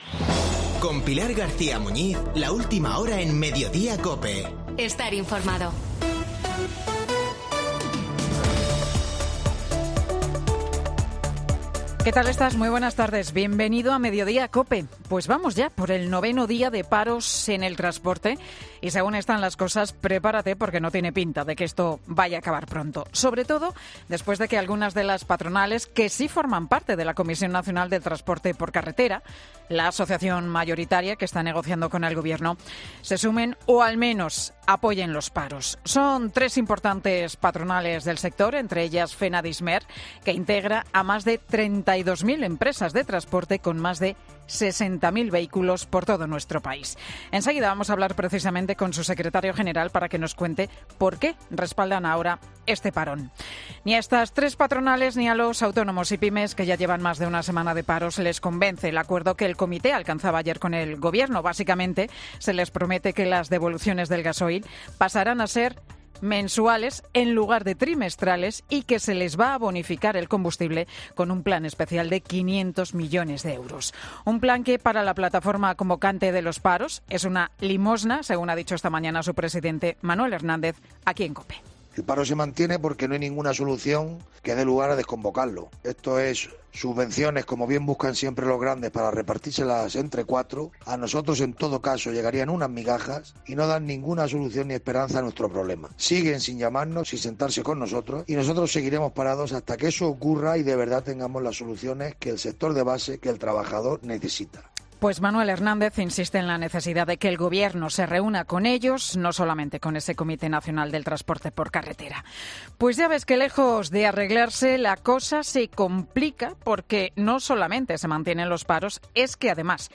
AUDIO: El monólogo de Pilar García Muñiz, en 'Mediodía COPE'